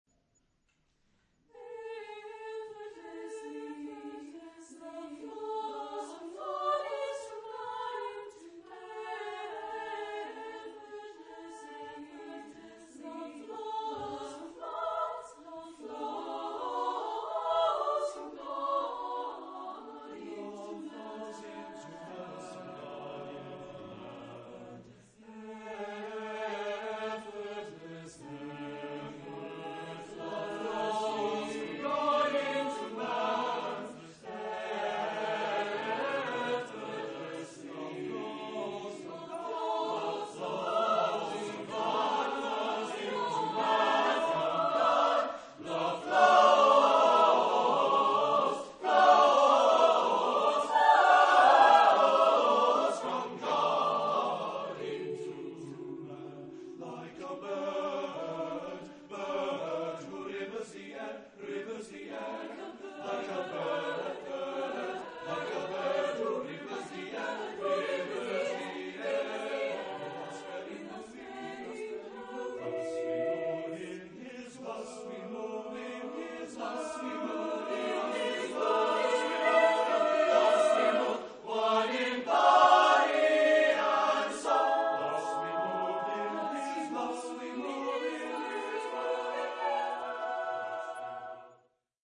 Tipo de formación coral: SATB  (4 voces Coro mixto )
Instrumentación: Piano